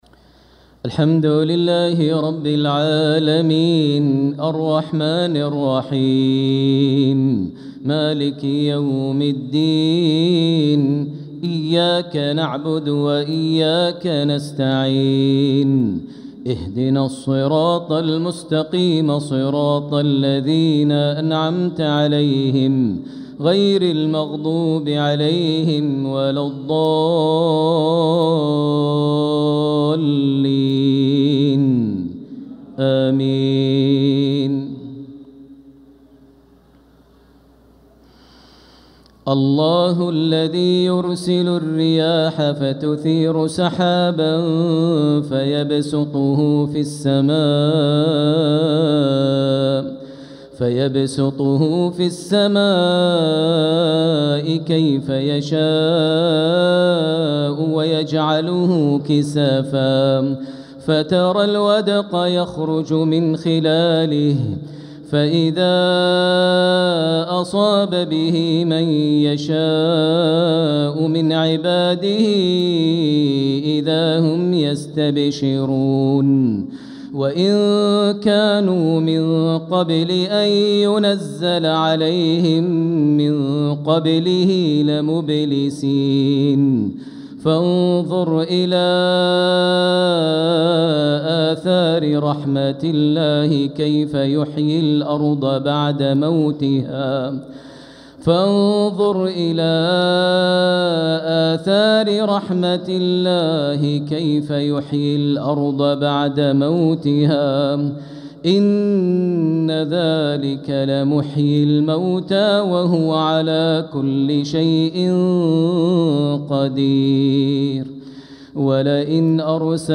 صلاة العشاء للقارئ ماهر المعيقلي 18 صفر 1446 هـ
تِلَاوَات الْحَرَمَيْن .